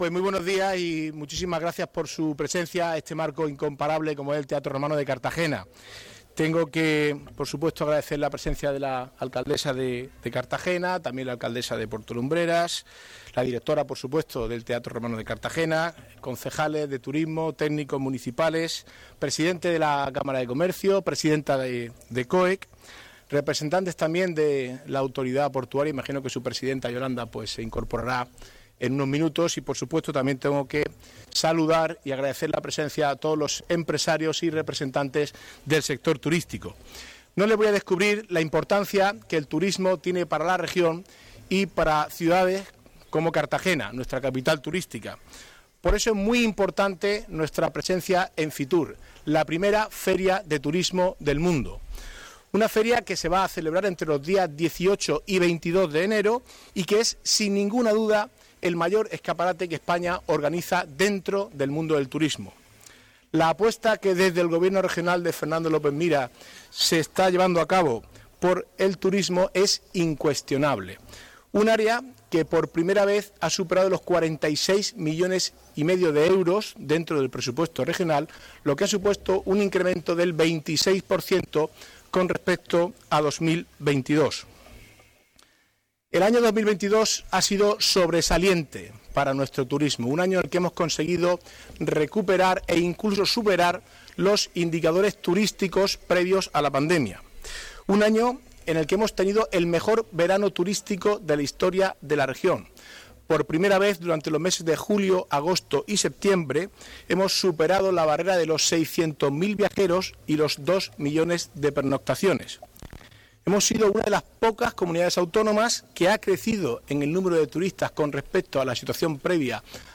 Enlace a Declaraciones de la alcaldesa y del Consejero de Turismo